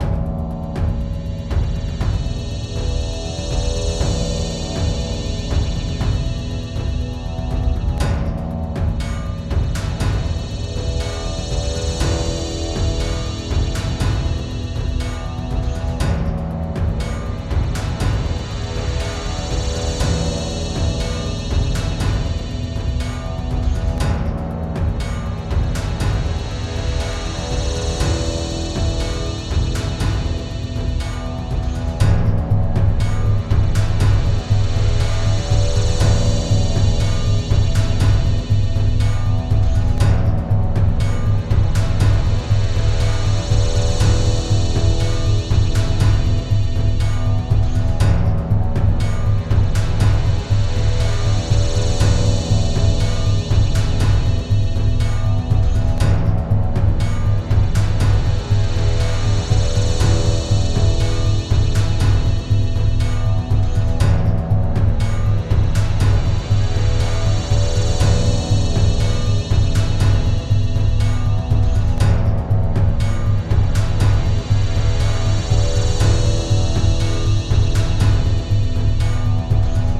Short loopablle "belly" cinematic underscore thingie